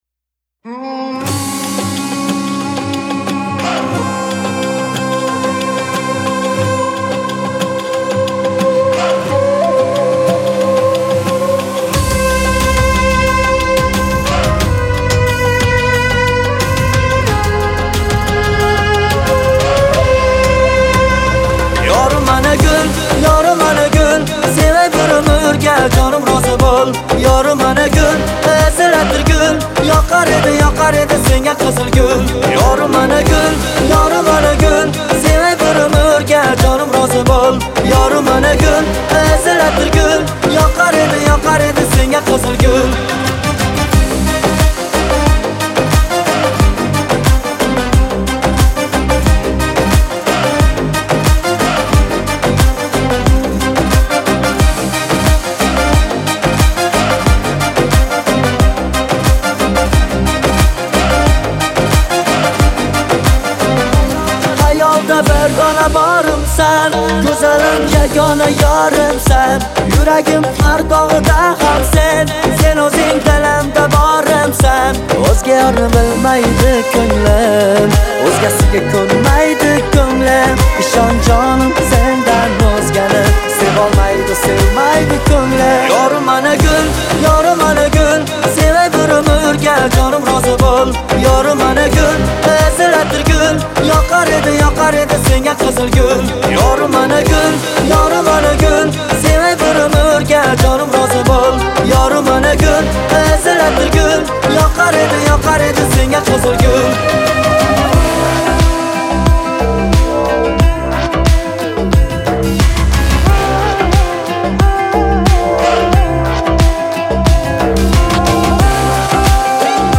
• Жанр: Турецкие песни